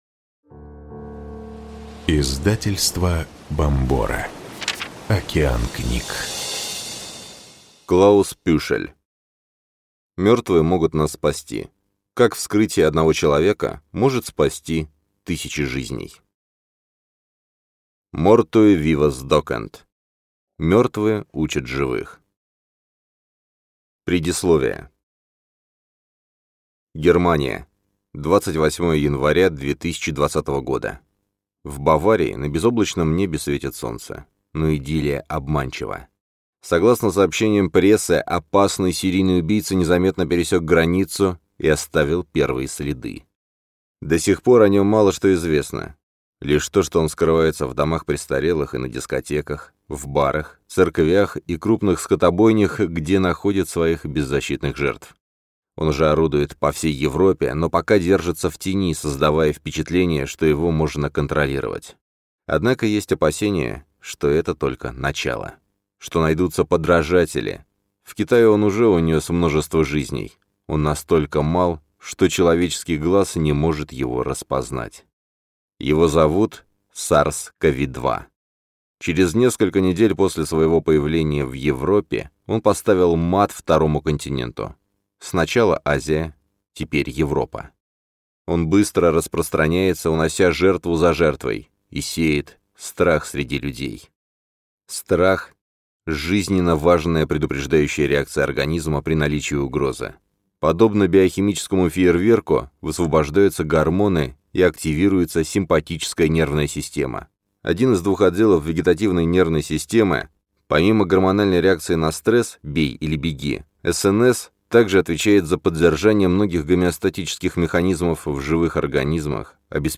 Аудиокнига Мертвые могут нас спасти. Как вскрытие одного человека может спасти тысячи жизней | Библиотека аудиокниг